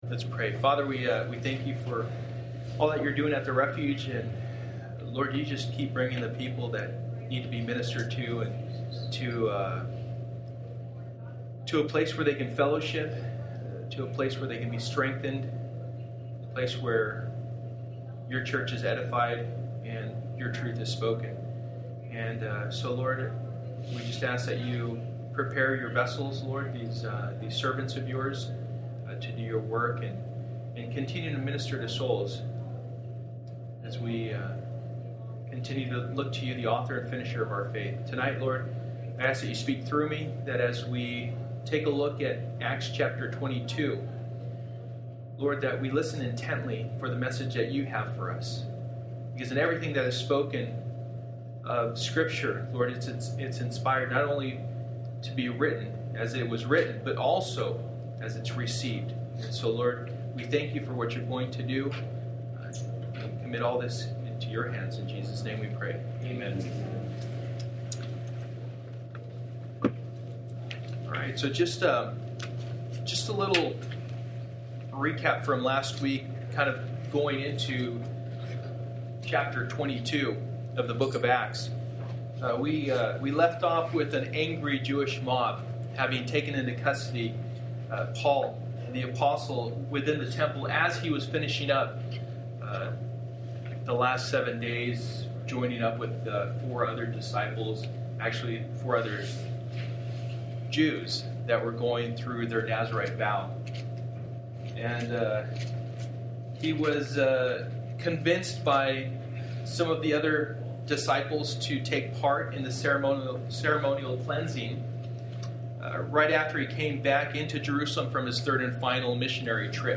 Acts 22:1-30 Service: Wednesday Night %todo_render% « Understanding God’s Vision for the Church